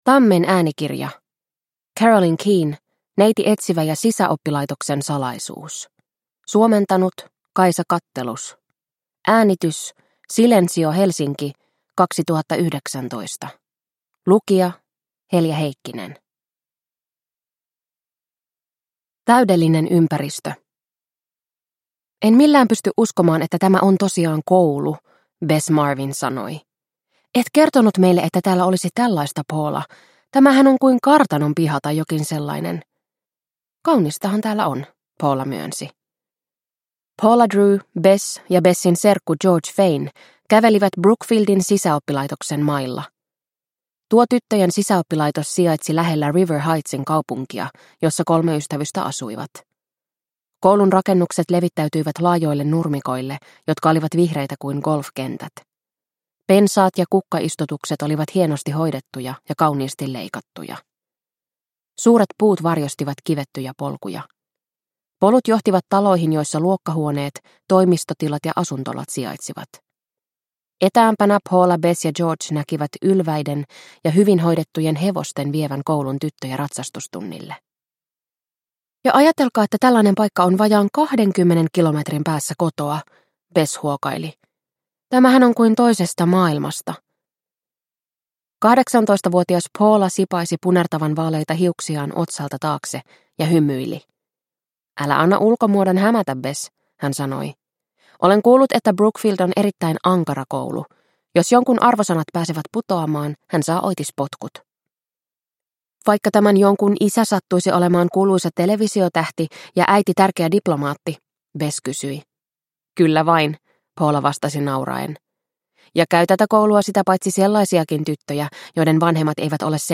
Neiti Etsivä ja sisäoppilaitoksen salaisuus – Ljudbok – Laddas ner